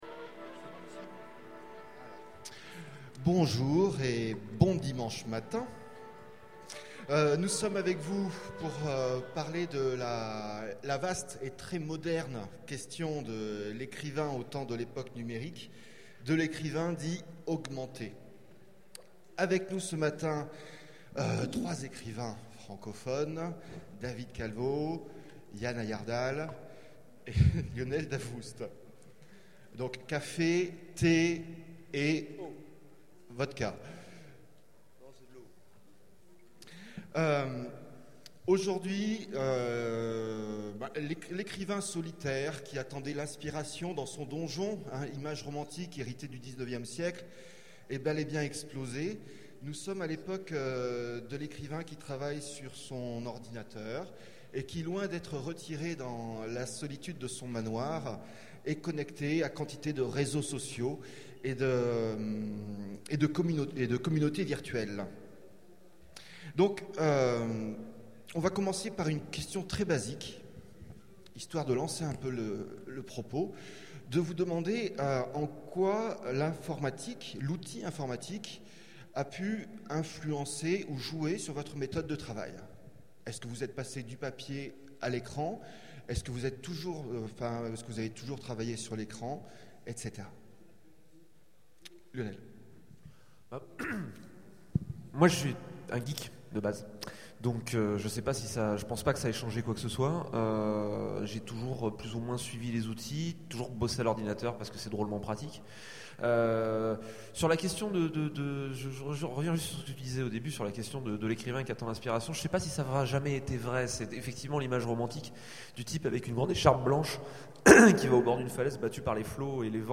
Utopiales 12 : Conférence Ces écrivains augmentés